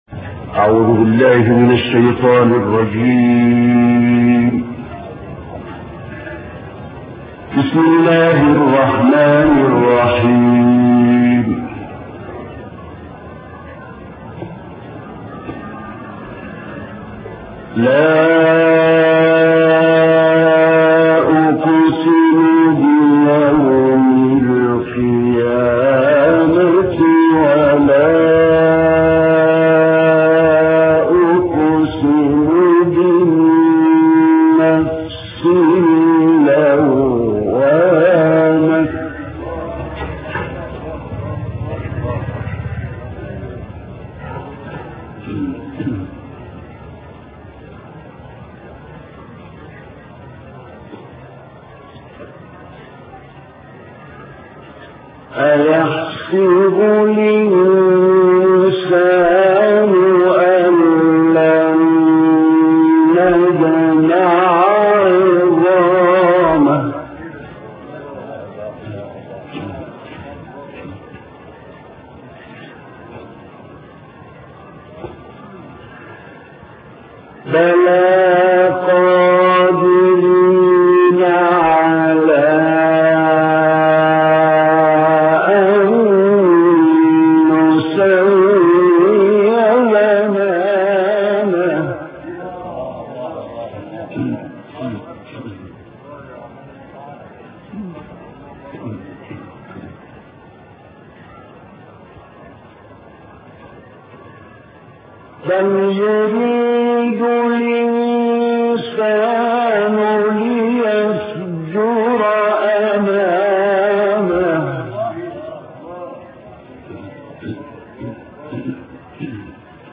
گروه چندرسانه‌ای ــ تلاوت آیات دلنشین سوره‌های مبارکه قیامت، تکویر، طارق و فجر را با صدای محمد الصیفی، ملقب به ابوالقراء می‌شنوید. این تلاوت در مقام‌های بیات، حجاز، رست، صبا، چهارگاه و سه‌گاه اجرا شده است.